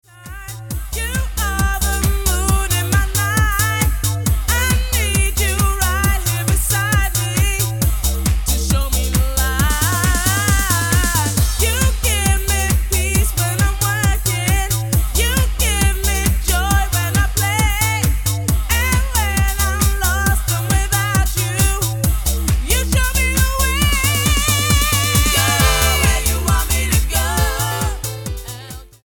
Dance/Electronic
Techno team